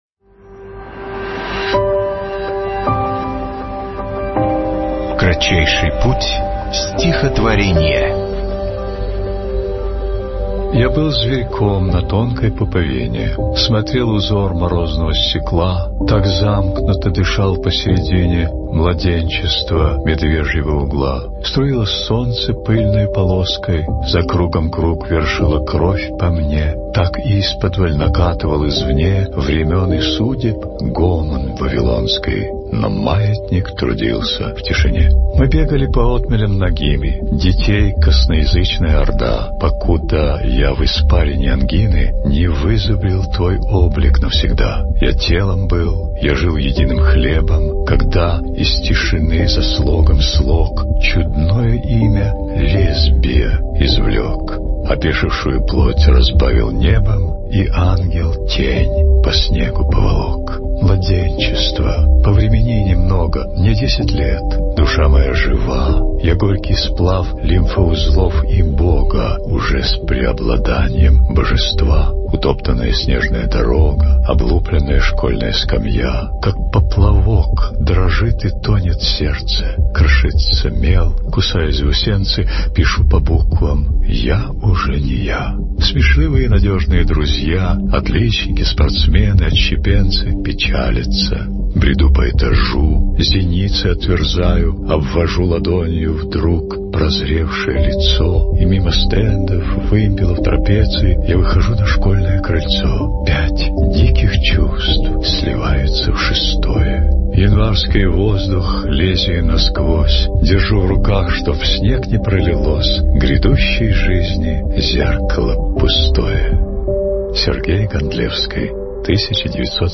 Gandlevskiy-Ya-byl-zverkom-na-tonkoy-pupovine.-chitaet-Nikolaj-Marton-stih-club-ru.mp3